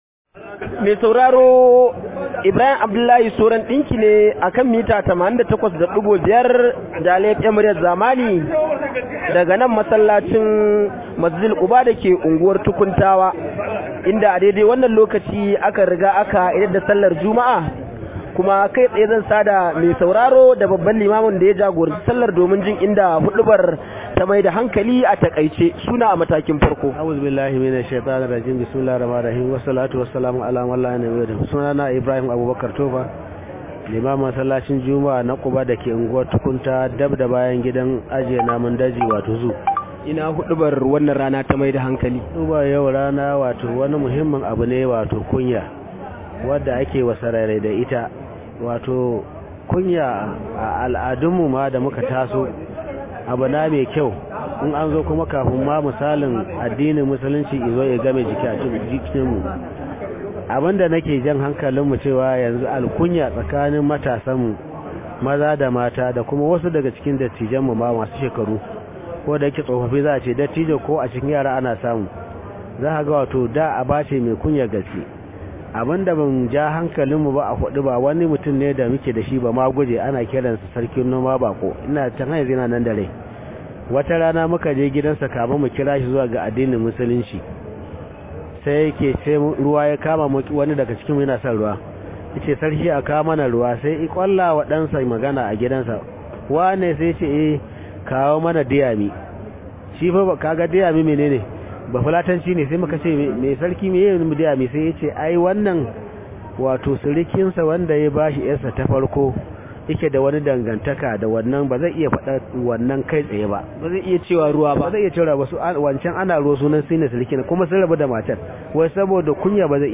Rahoton Juma’a: Kunya yanzu ta yi karanci a tsakanin al’umma – Limami